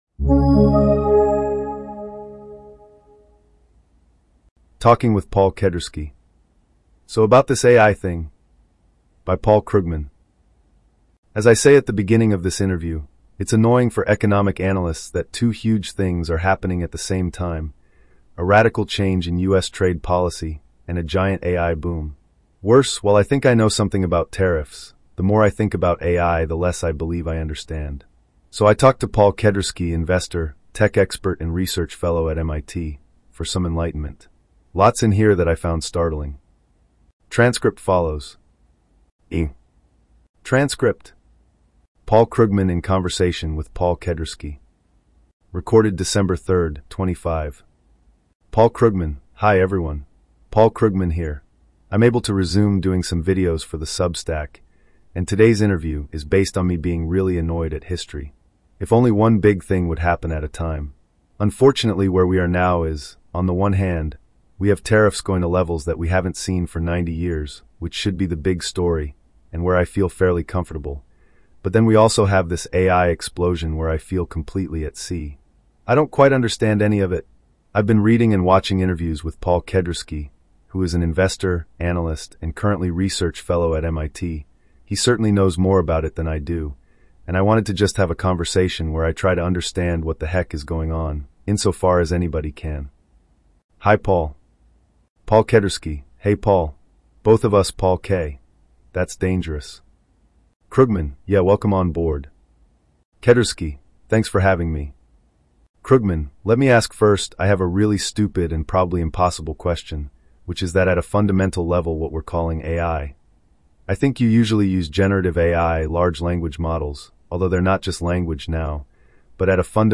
en-US-OnyxTurboMultilingualNeural.mp3